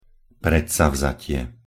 pronunciation_sk_predsavzatie.mp3